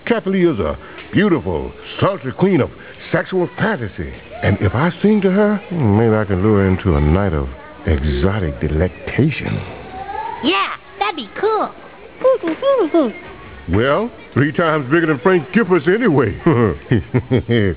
- Chef telling the children how large he is. 139kb